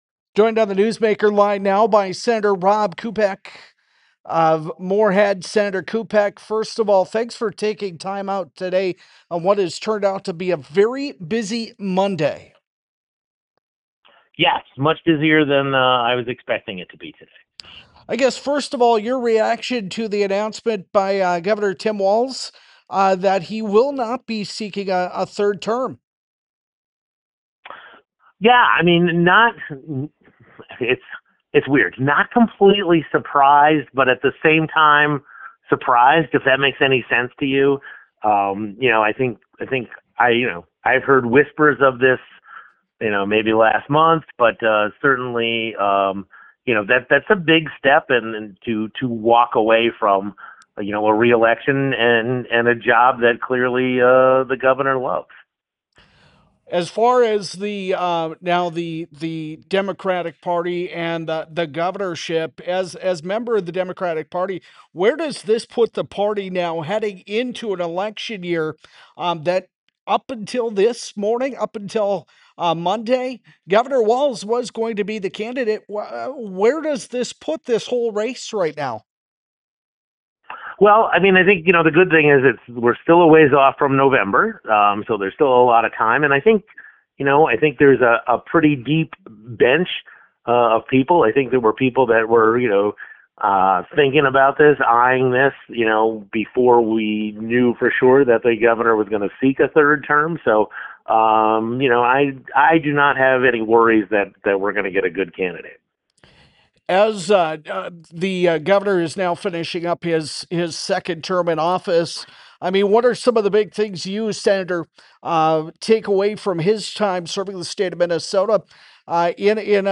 He told Flag Family News in a phone interview Monday afternoon that he had heard ‘whispers’ of Walz’s announcement as early as last month.